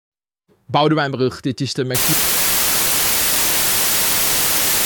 Verstoorde oproep